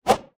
punch_short_whoosh_16.wav